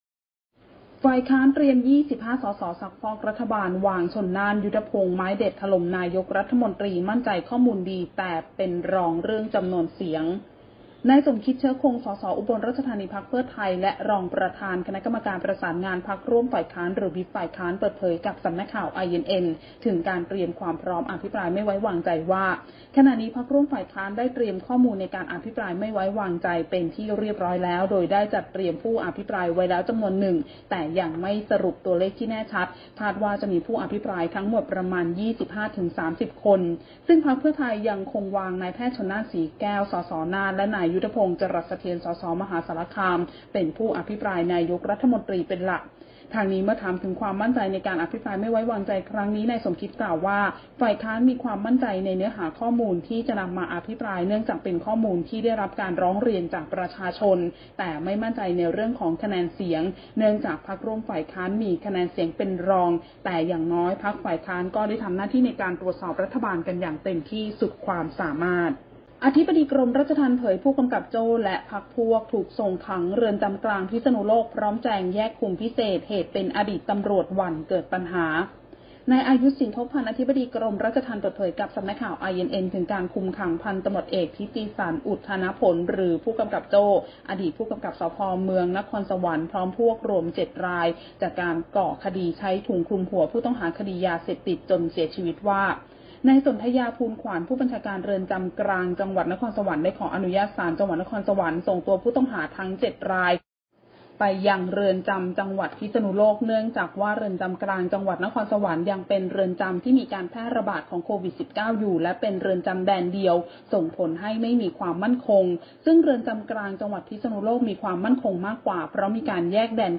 คลิปข่าวต้นชั่วโมง
ข่าวต้นชั่วโมง 08.00 น.